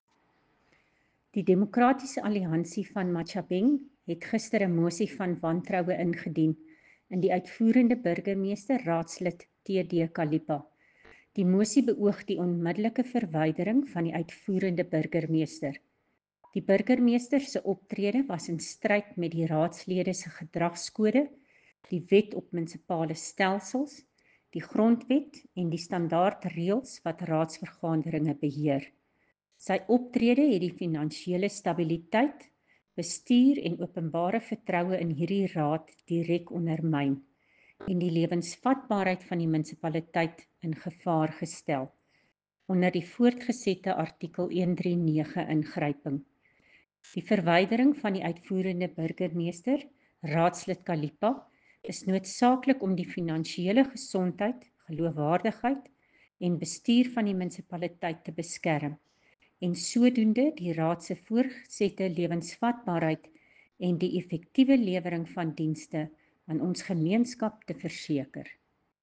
Afrikaans soundbites by Cllr Coreen Malherbe and